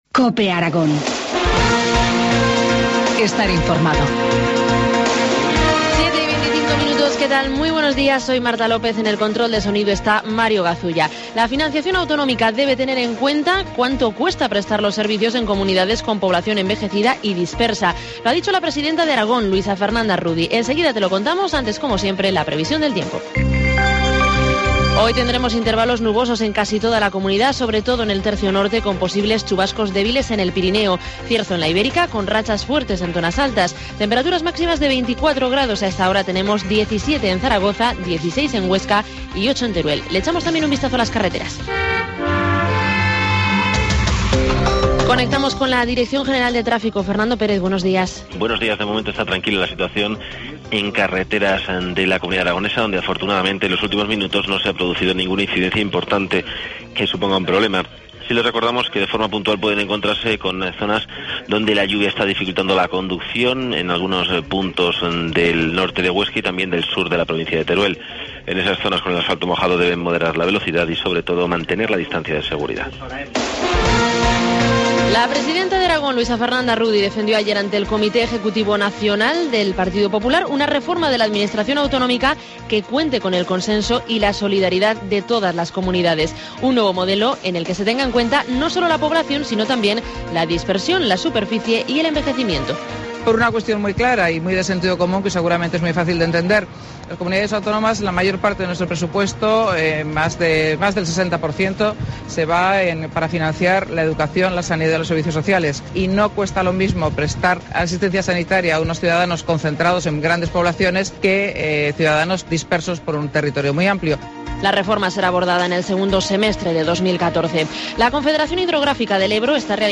Informativo matinal, martes 15 de octubre, 7.25 horas